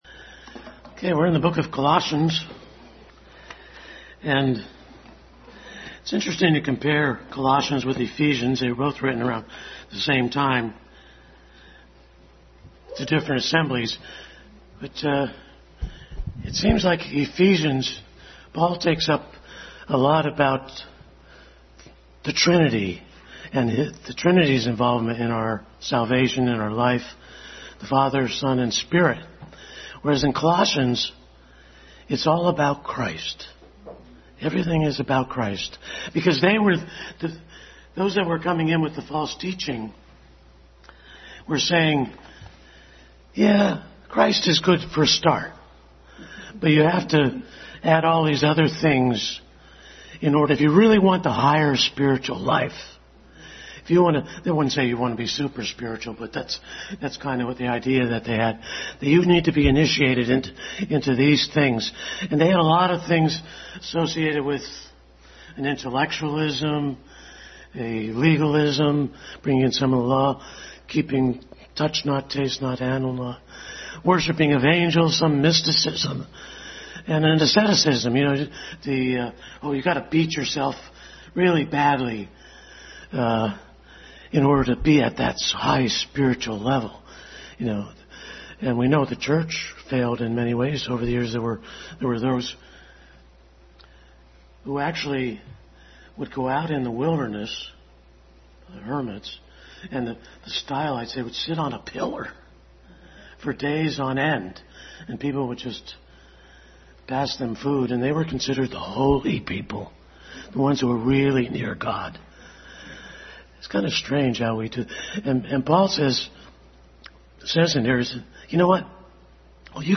Adult Sunday School continued study in Colossians.
Colossians 3:5-17 Service Type: Sunday School Adult Sunday School continued study in Colossians.